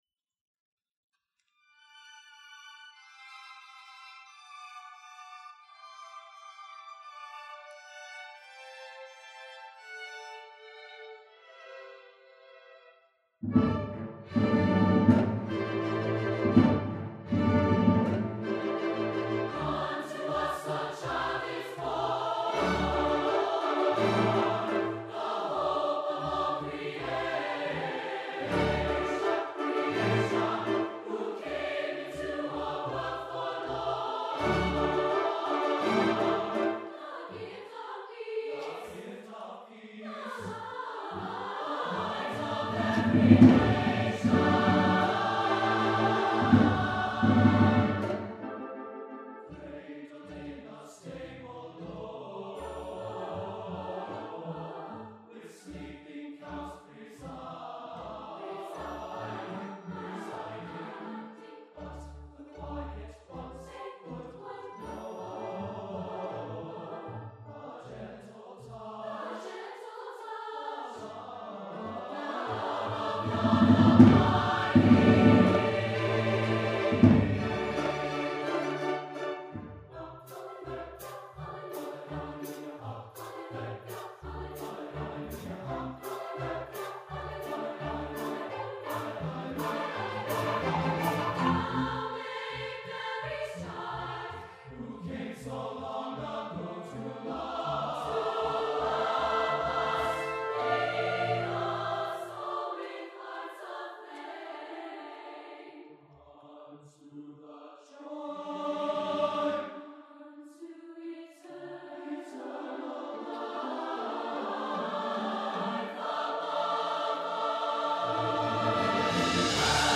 for SATB Chorus and Orchestra (2009)